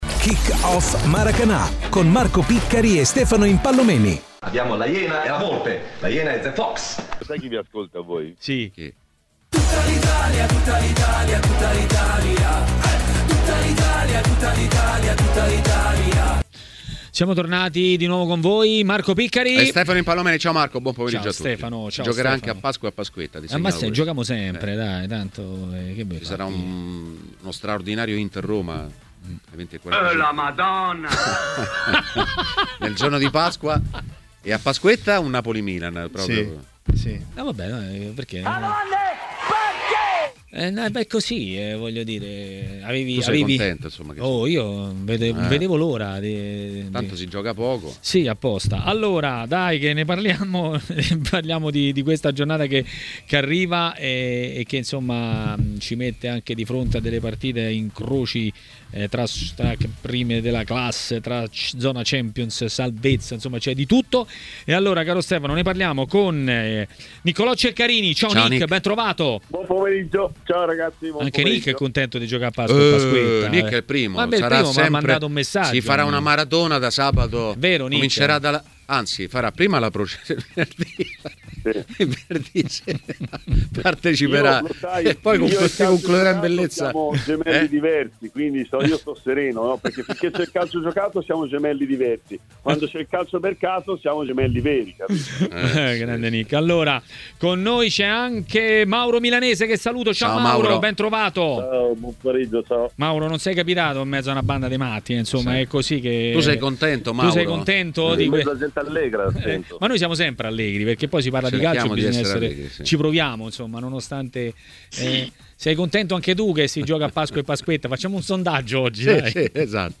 Ospite di Maracanà, nel pomeriggio di TMW Radio